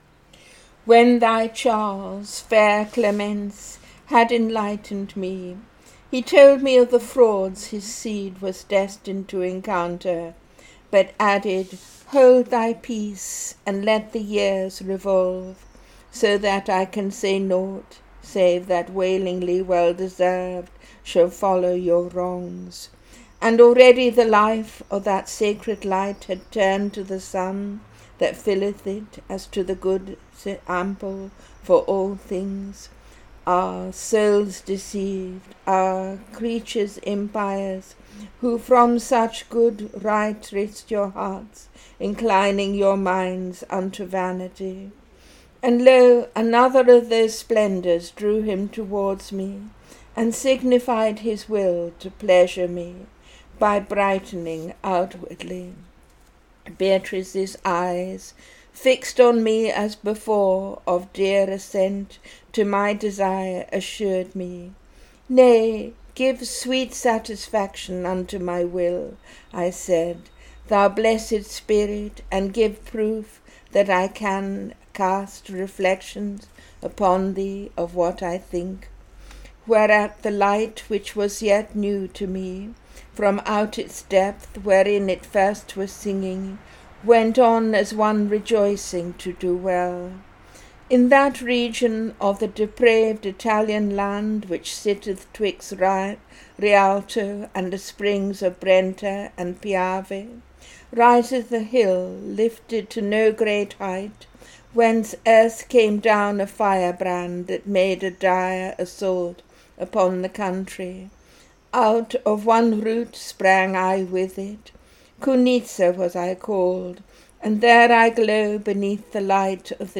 Temple Classics, reading in English